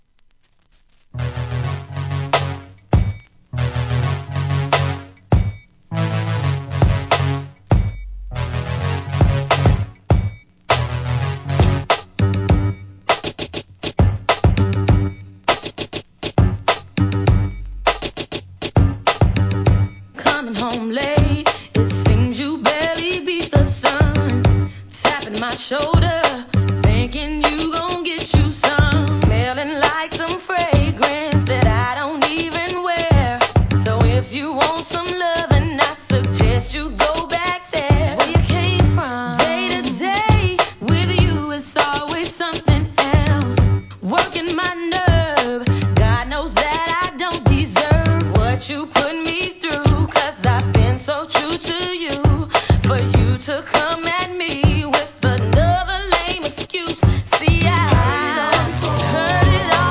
HIP HOP/R&B
MUSHUP,ブレンド物!!